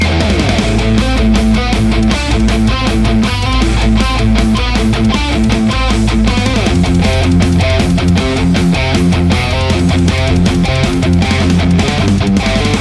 The JCM900 pack includes captures ranging from clean tones to heavily distorted and everything in between plus my personal YouTube IR that I use in my demos are also included.
Metal Riff Mix
RAW AUDIO CLIPS ONLY, NO POST-PROCESSING EFFECTS